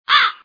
Crow Sound Button - Bouton d'effet sonore